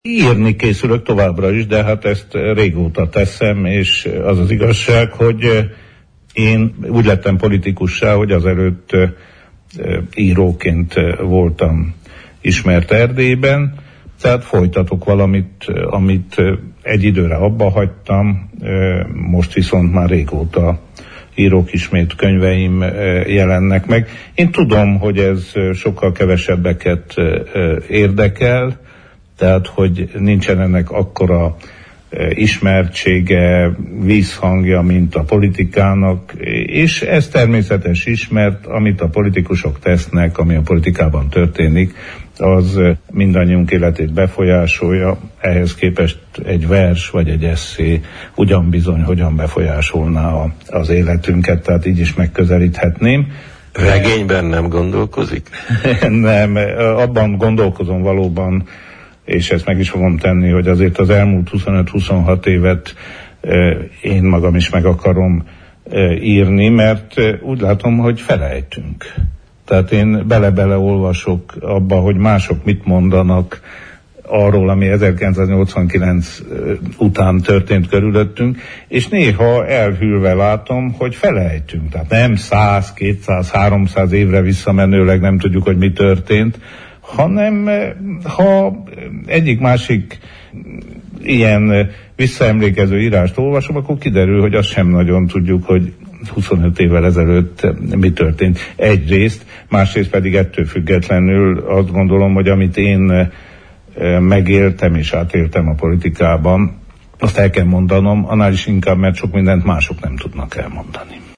Erről a szeptember 1-jén, csütörtökön elhangzott Naprakész műsorban beszélt a szenátor, az RMDSZ volt szövetségi elnöke